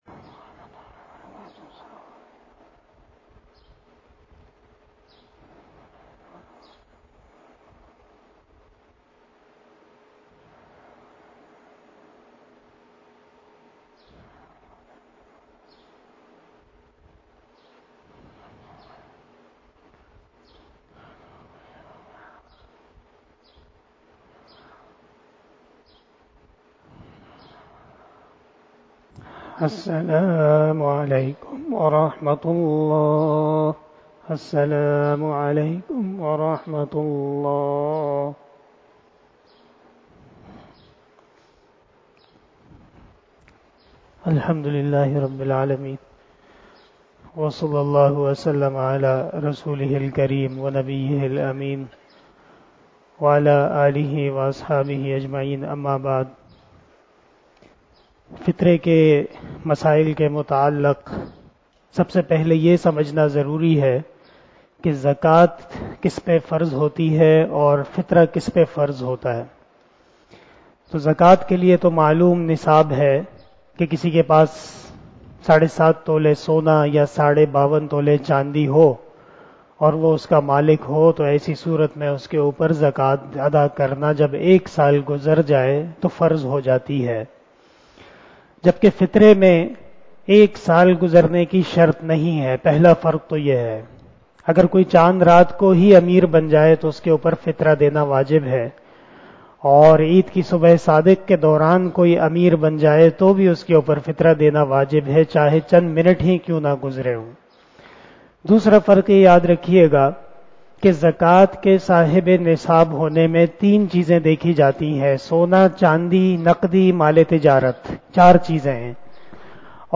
047 After Traveeh Namaz Bayan 18 April 2022 ( 17 Ramadan 1443HJ) Monday